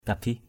/ka-bih/ (d.) tên một vị vua Champa (1694-1530) = nom d’un roi Cam.